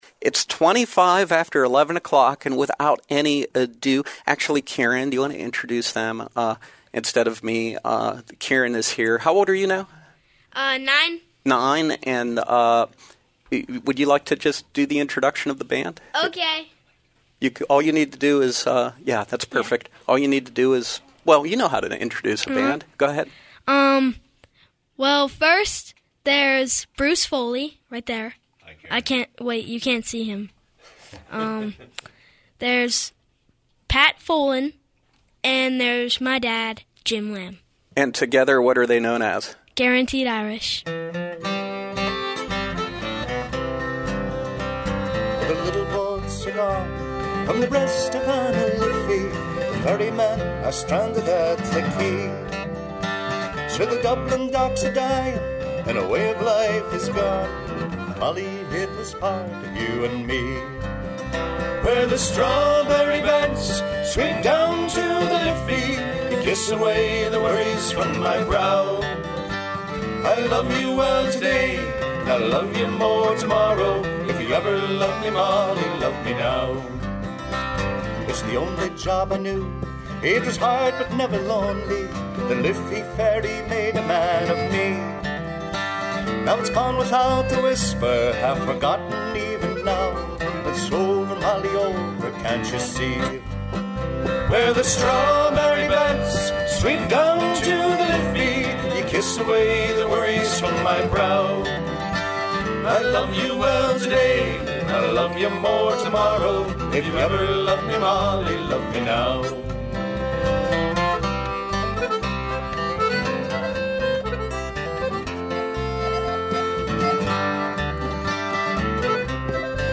Irish ballads and dance music
button accordion, guitars